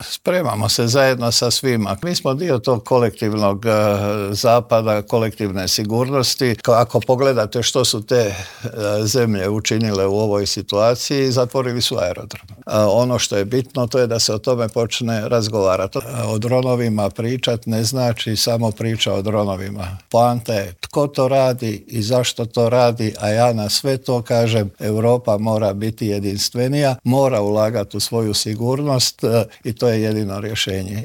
Više puta tu rečenicu izgovorio je i potpredsjednik Vlade i ministar unutarnjih poslova Davor Božinović kojega smo u Intervjuu tjedna Media servisa pitati zašto je to tako.